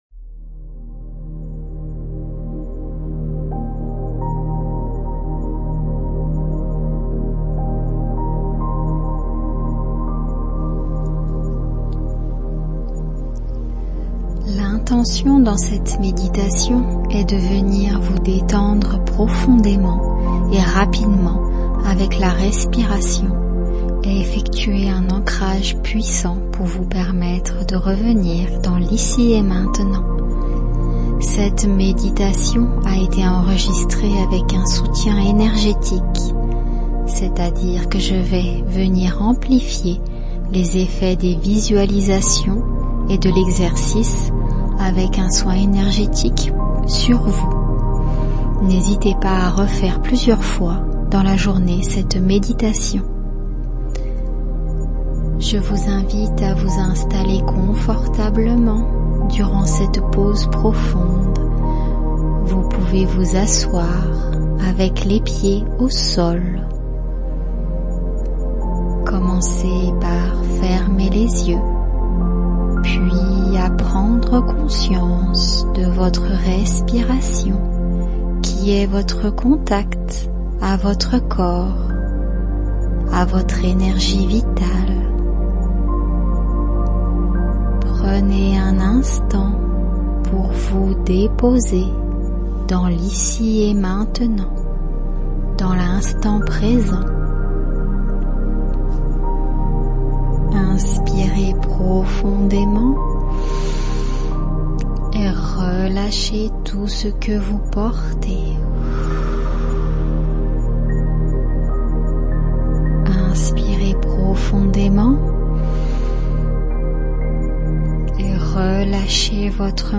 méditation
Méditation maman en Burn-out.mp3